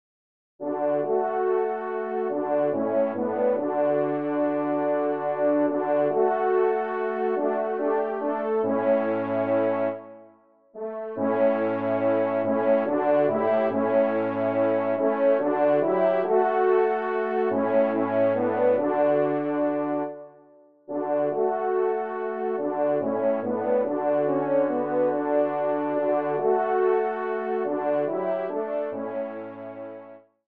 Genre :  Musique religieuse pour Trompes ou Cors
3ème et 4ème Trompes